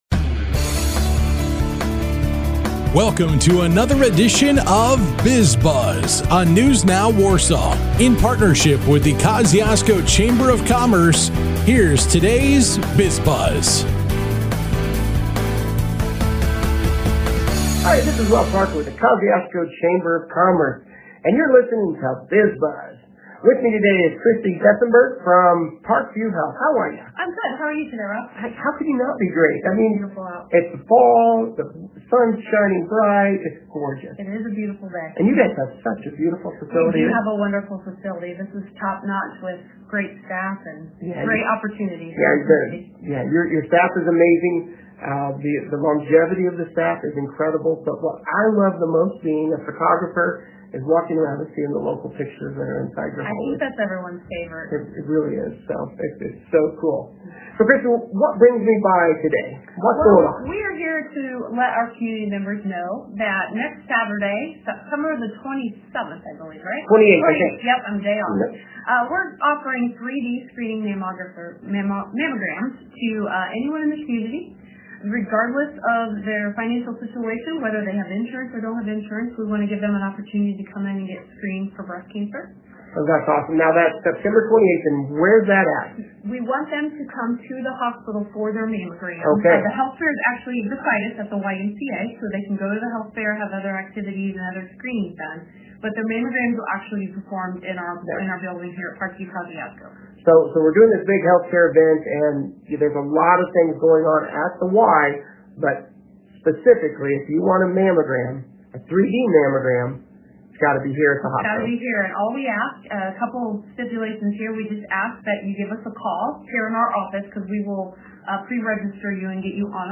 A chat with Parkview Health, Parkview YMCA, and Lutheran Kosciusko Hospital.